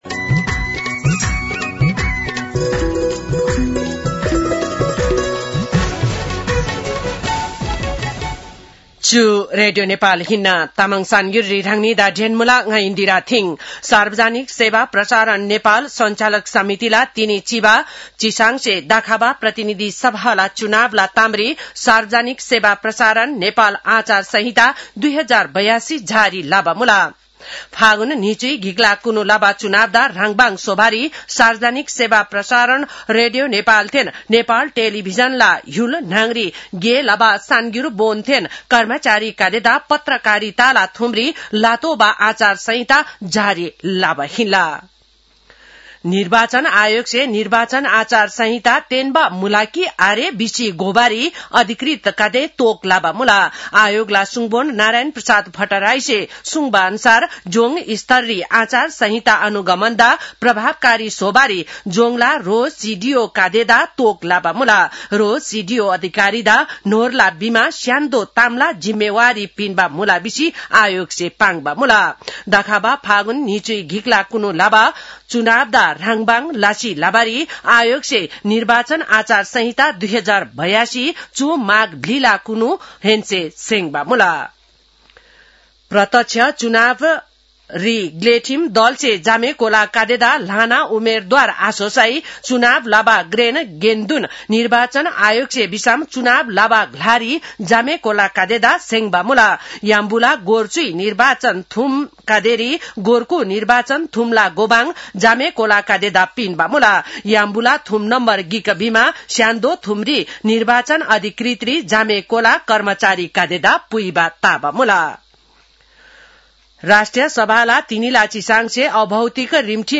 तामाङ भाषाको समाचार : १४ माघ , २०८२